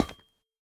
Minecraft Version Minecraft Version snapshot Latest Release | Latest Snapshot snapshot / assets / minecraft / sounds / block / copper / step1.ogg Compare With Compare With Latest Release | Latest Snapshot